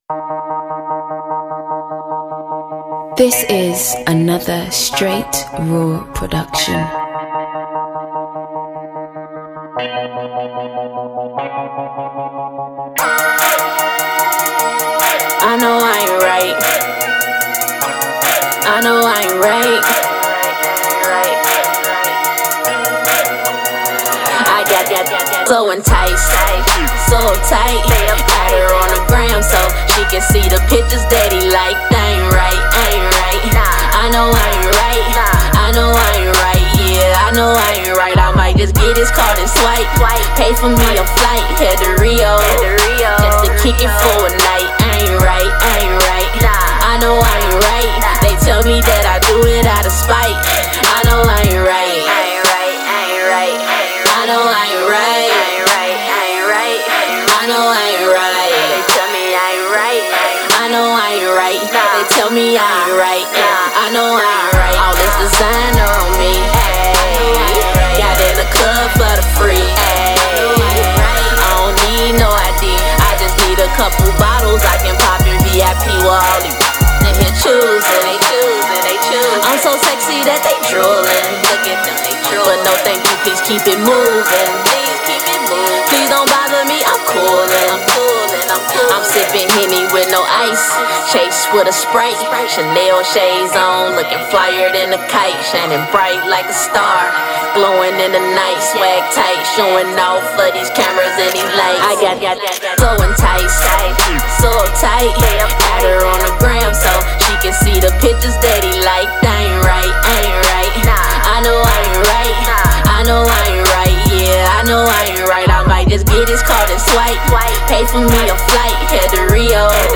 Hiphop
female hip-hop